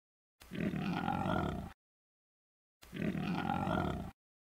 Звук зверя